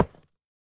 Minecraft Version Minecraft Version snapshot Latest Release | Latest Snapshot snapshot / assets / minecraft / sounds / block / deepslate / place2.ogg Compare With Compare With Latest Release | Latest Snapshot